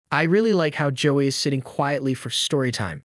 The adult draws attention to examples of the positive behavior.